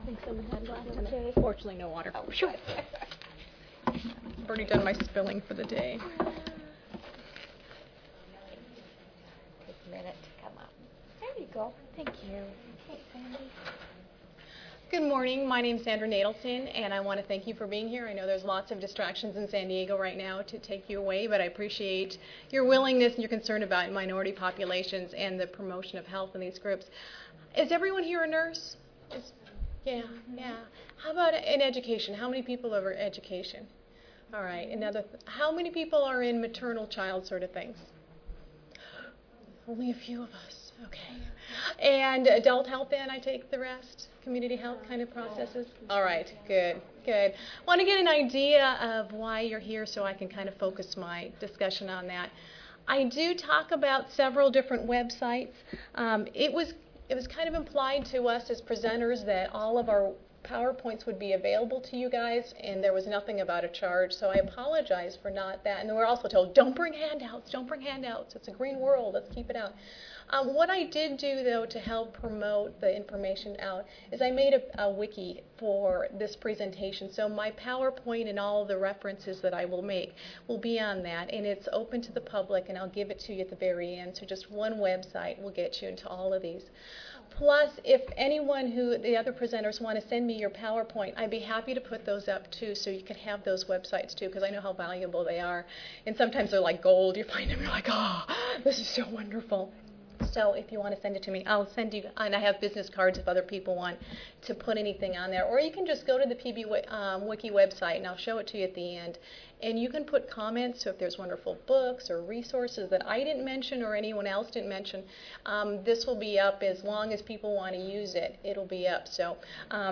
5134.0 Teaching Cultural Competency Wednesday, October 29, 2008: 10:30 AM Oral The relationship between culture, communities and health are described in the papers presented in this session. Acquiring cultural competency for public health students is discussed.